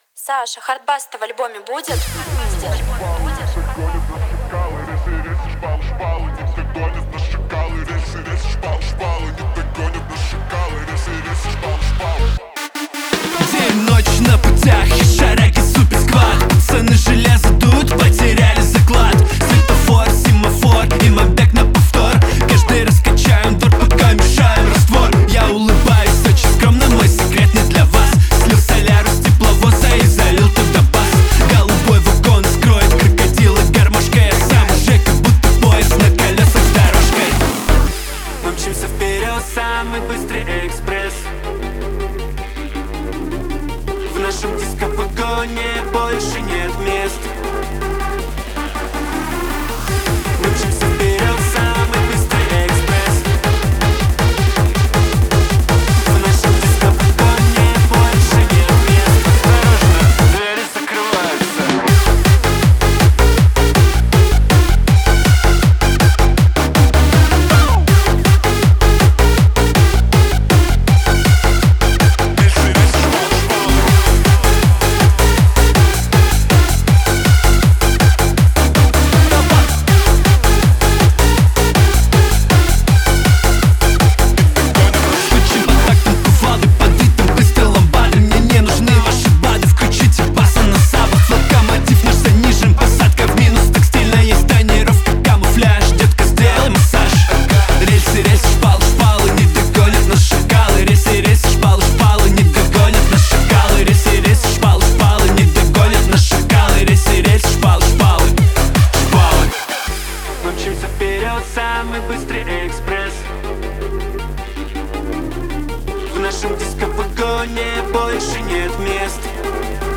Skurt_ZhD_Hardbass.mp3